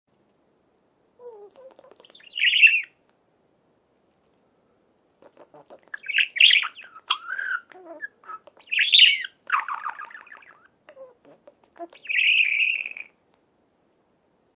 دانلود صدای مرغ عشق برای ساخت کلیپ و تدوین از ساعد نیوز با لینک مستقیم و کیفیت بالا
جلوه های صوتی
برچسب: دانلود آهنگ های افکت صوتی انسان و موجودات زنده دانلود آلبوم صدای مرغ عشق از افکت صوتی انسان و موجودات زنده